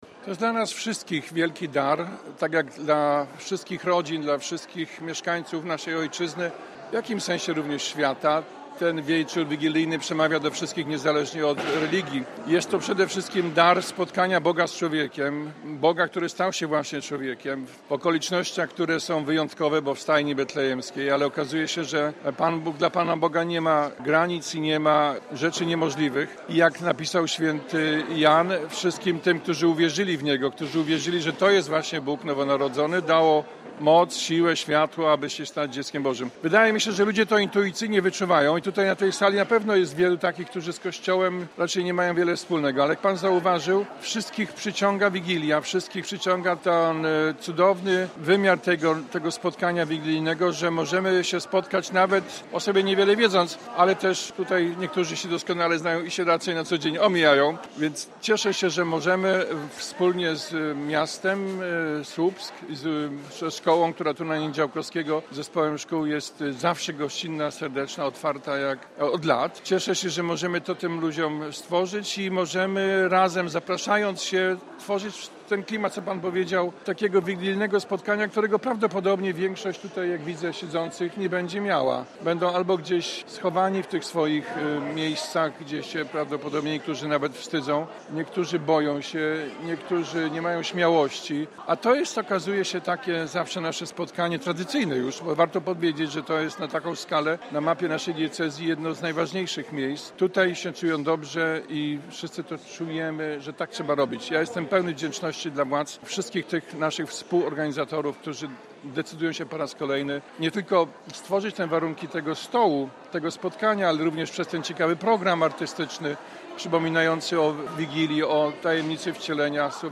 – Dla wielu to jedyna w tym roku okazja, aby podzielić się opłatkiem – mówi Krzysztof Zadarko, biskup diecezji koszalińsko-kołobrzeskiej.
Uroczysta kolacja, kolędowanie oraz świąteczne życzenia – tak wyglądała kolejna wigilia zorganizowana przez słupską Caritas.